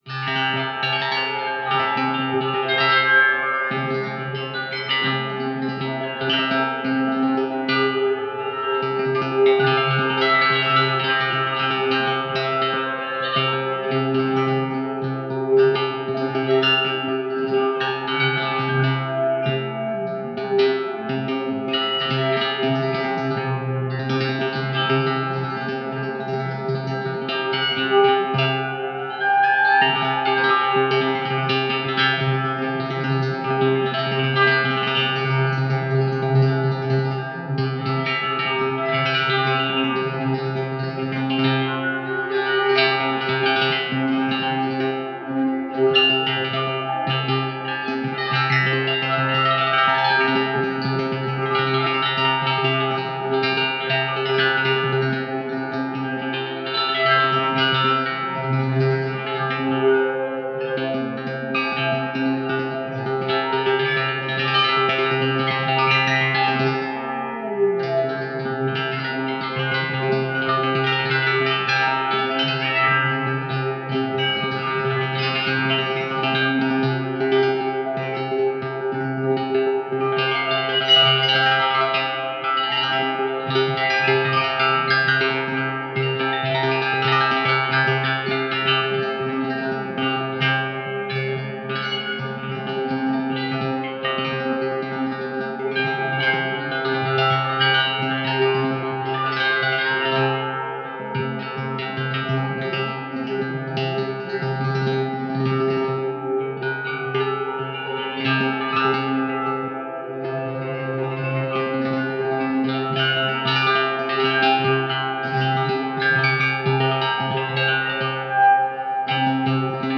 大量のギター音が、複雑に重ねられていますが、濁ることなく、音が澄んでいて気持ち良いのは、ハーモニクスならではのもの。
楽器演奏のはずなのに、聴き入っていると、いつの間にか、まるで自然の環境音に包まれているような錯覚に陥りました。
ギター愛好家の方々にはもちろん、現代音楽、先端的テクノ、実験音楽をお好きな方々にもお薦めのアルバムです。